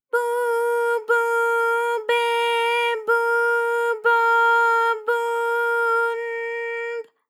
ALYS-DB-001-JPN - First Japanese UTAU vocal library of ALYS.
bu_bu_be_bu_bo_bu_n_b.wav